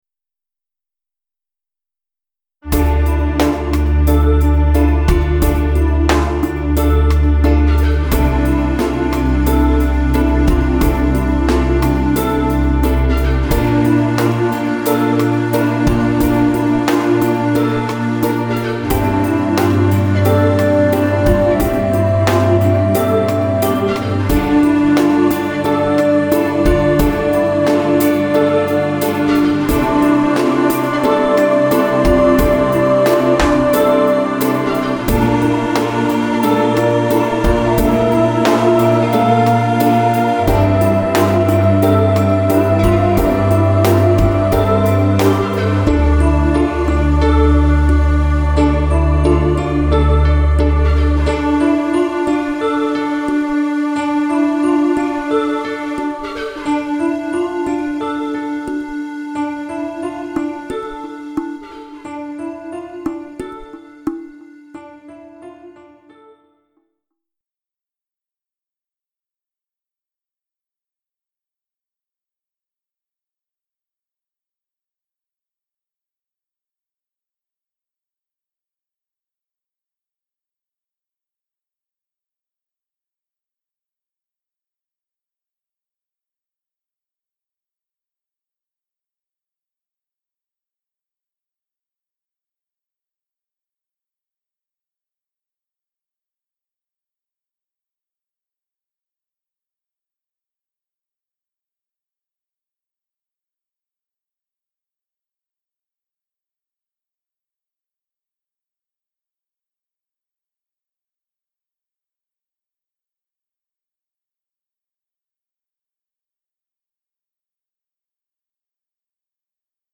Part of a musical suite of Native American music. As the cycle continues I try to create a musical theme that represents a young Navajo warrior's initiation to true manhood. As he embarks on his first battle march.